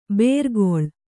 ♪ bērgoḷ